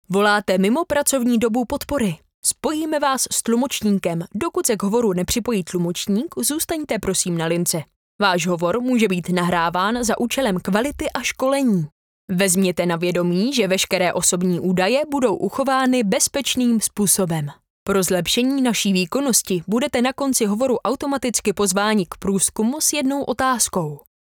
Ženský reklamní voiceover do jedné minuty
Natáčení probíhá v profesiálním dabingovém studiu.